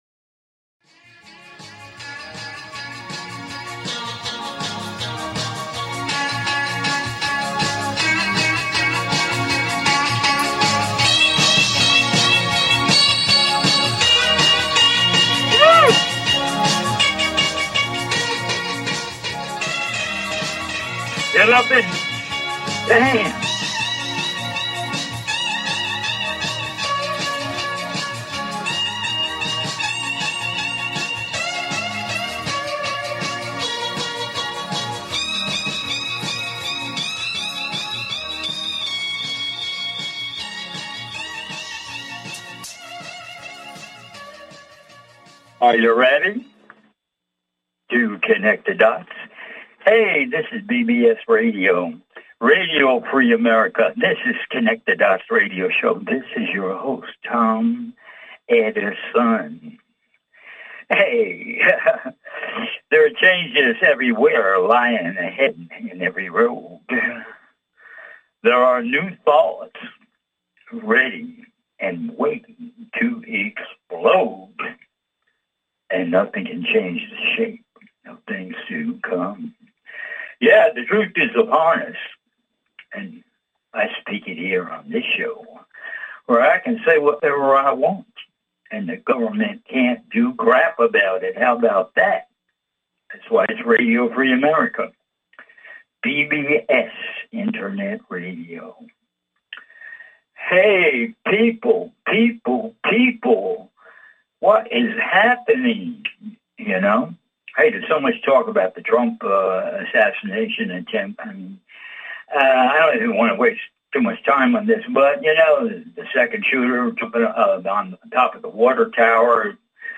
"CONNECT THE DOTS" is a call in radio talk show, where I share my knowledge of the metaphysical, plus ongoing conspiracies, plus the evolution of planet earth - spiritual info - et involvement - politics - crystals - etc.